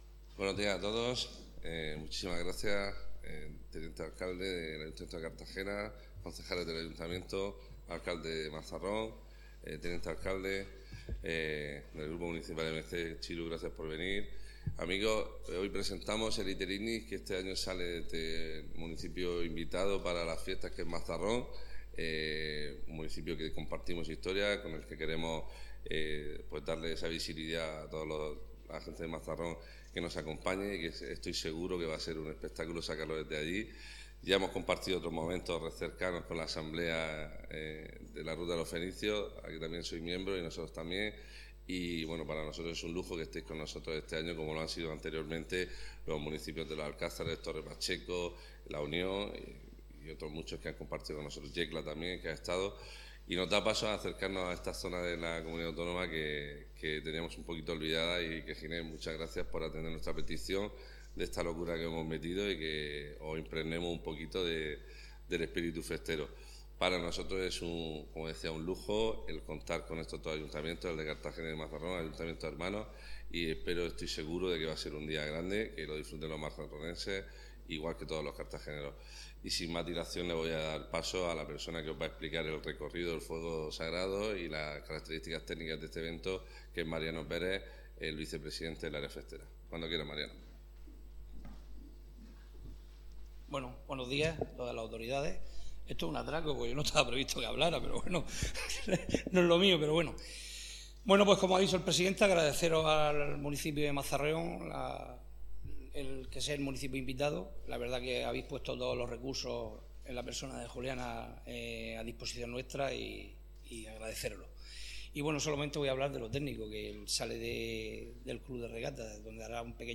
Enlace a Presentación del traslado del Fuego Sagrado desde Mazarrón